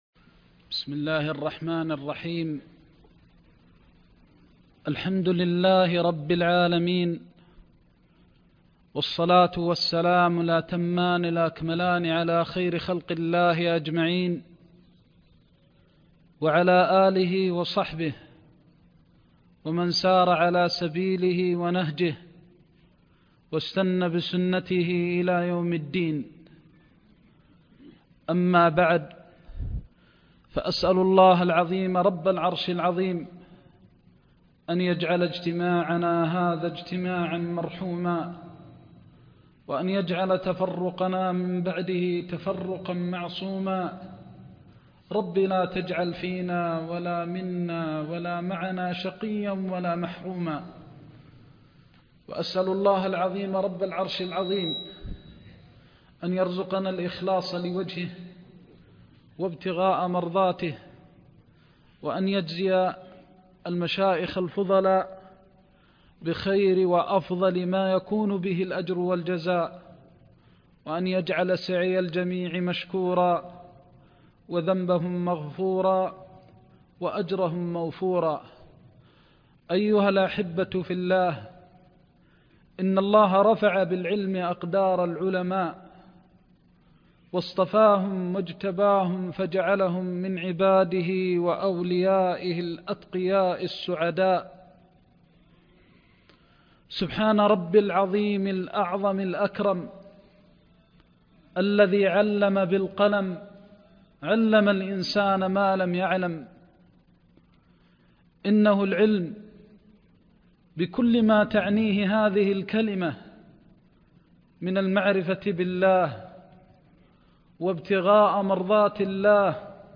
زاد المستقنع كتاب الطهارة (1) درس مكة